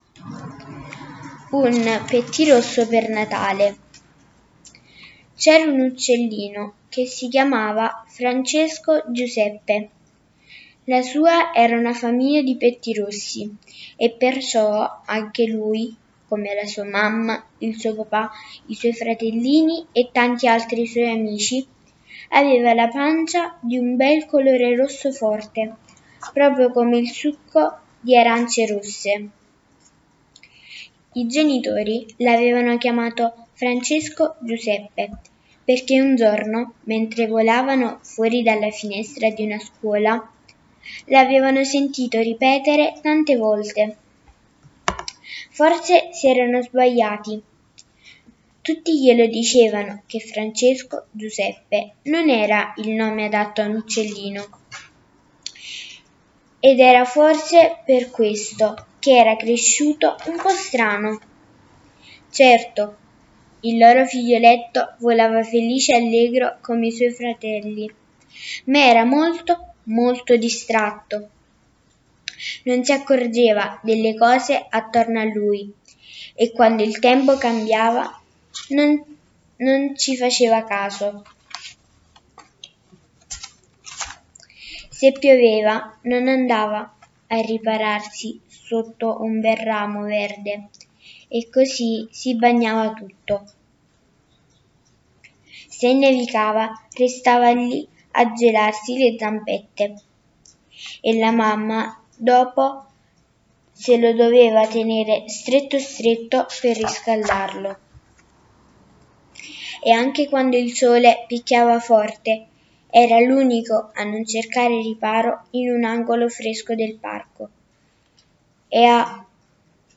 Un pettirosso per Natale | Oggi vi leggo la favola di Lia Levi